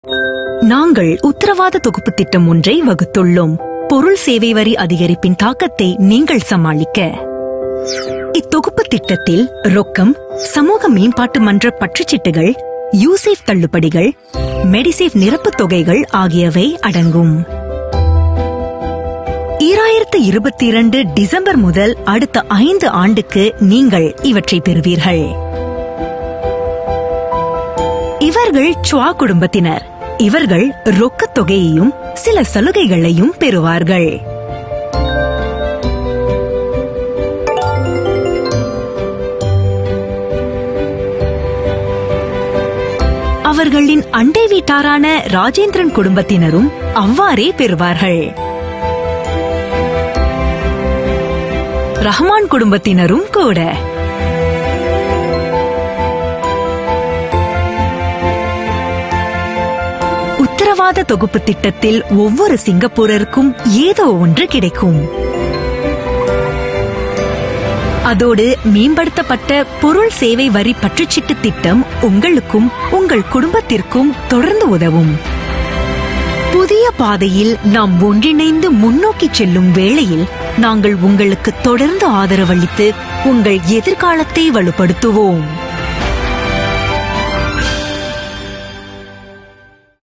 Voice Samples: Voice Sample 01